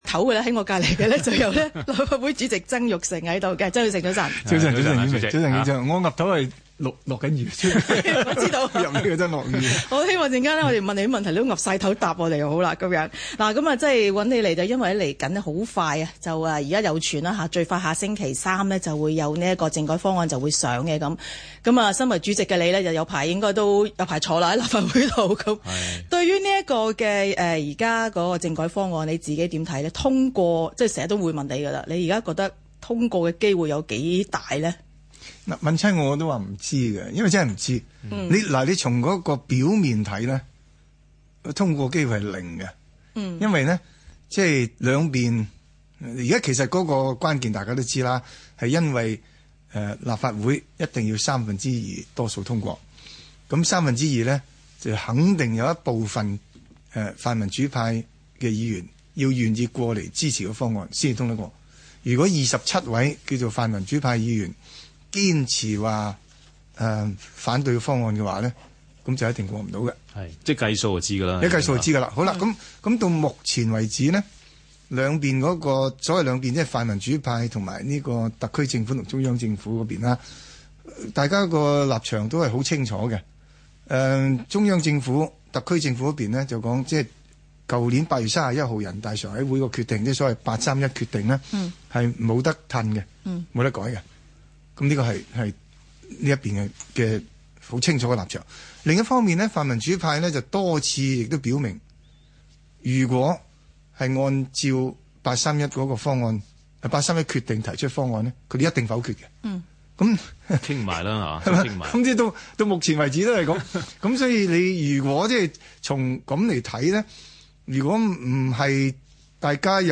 香港電台 《星期六問責》訪問